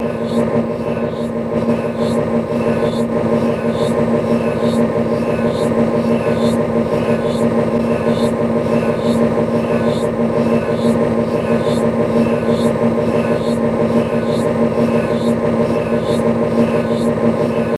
MRI 1.3 Guass Machine Reverse